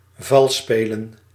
Ääntäminen
France: IPA: /ʁu.le/